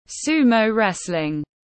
Môn đấu vật Nhật Bản tiếng anh gọi là sumo wrestling, phiên âm tiếng anh đọc là /ˌsuː.məʊ ˈres.lɪŋ/
Sumo wrestling /ˌsuː.məʊ ˈres.lɪŋ/